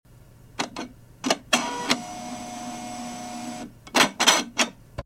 printing.mp3